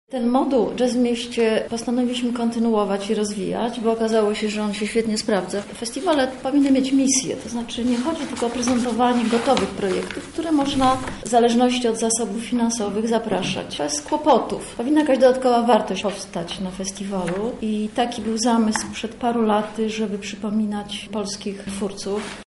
konferencja
konferencja.mp3